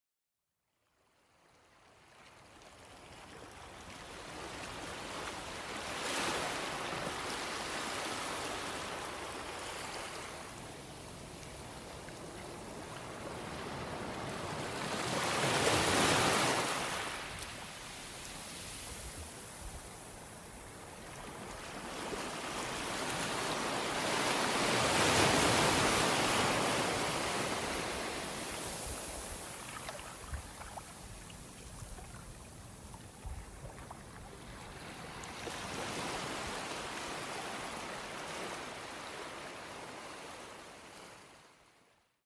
Naturgeräusche 2020
Meeresrauschen
Ich habe also alle Dateien selber aufgenommen und produziert.
Meeresrauschen-Probe.mp3